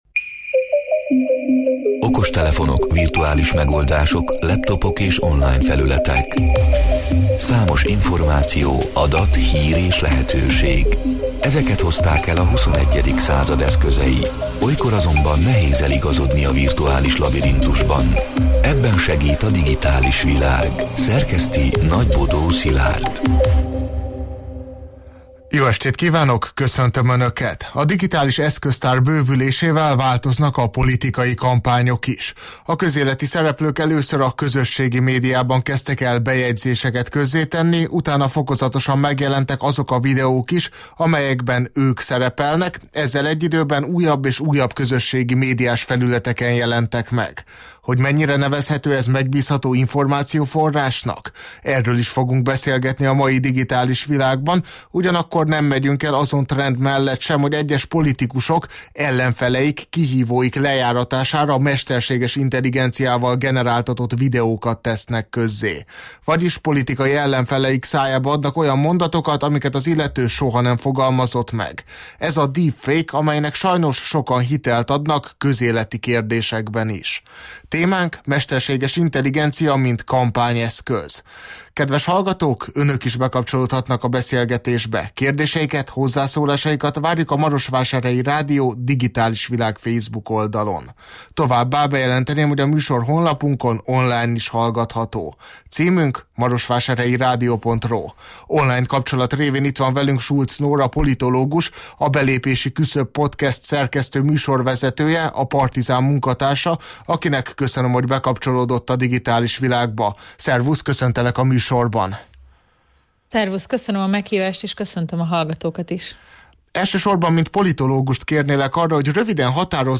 A Marosvásárhelyi Rádió Digitális Világ (elhangzott: 2025. november 18-án, kedden este nyolc órától élőben) c. műsorának hanganyaga: